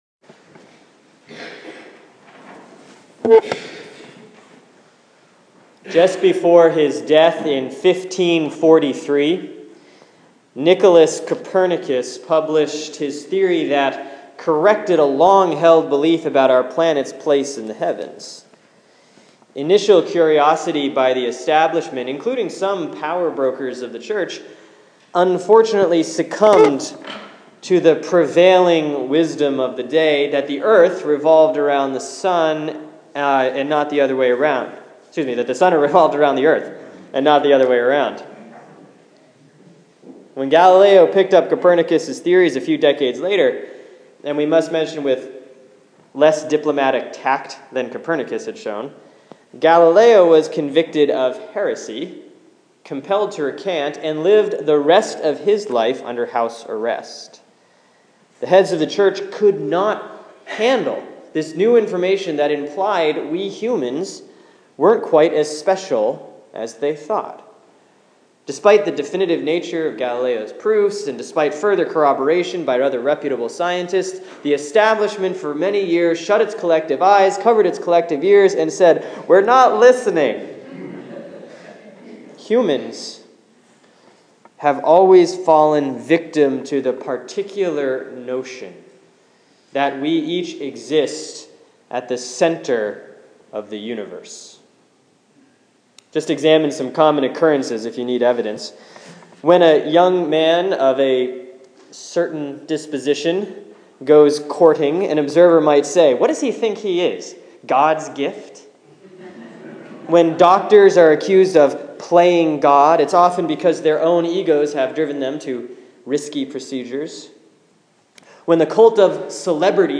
Sermon for Sunday, December 14, 2014 || Advent 3B || John 1:6-8, 19-28